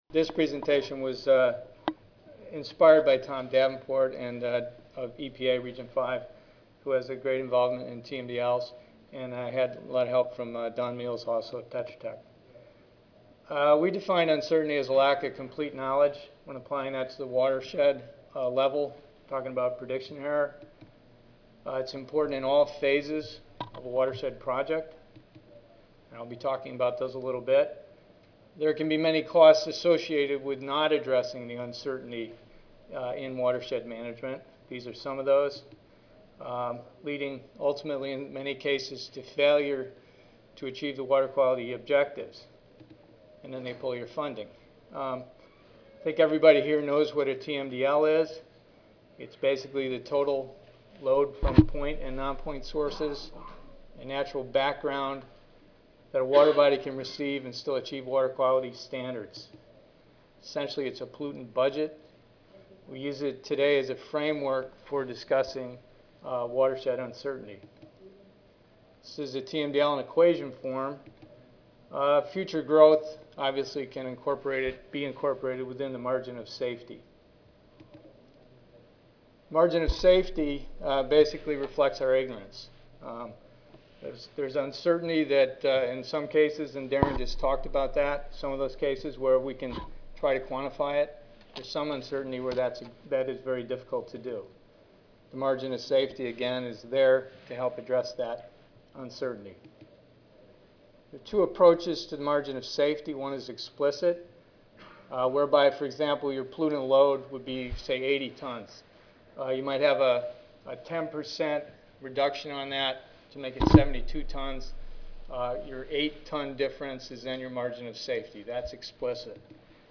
Inc. Audio File Recorded presentation